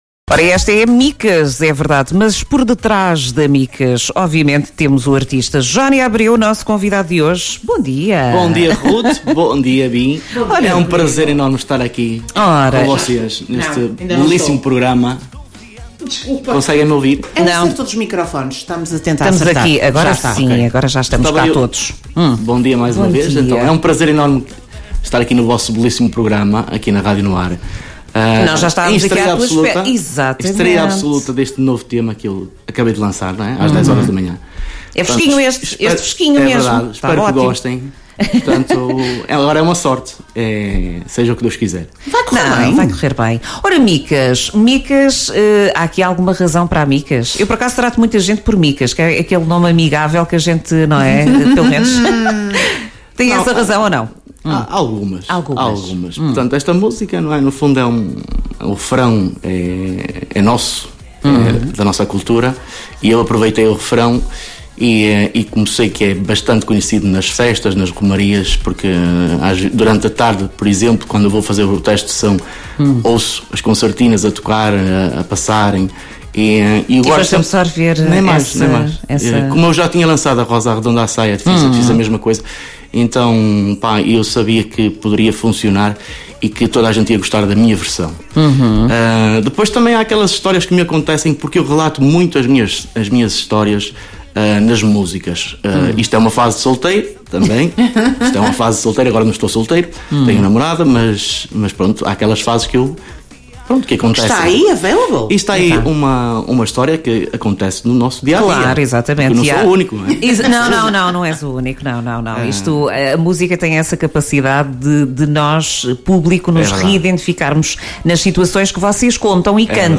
Entrevista em direto no programa “Manhãs NoAr”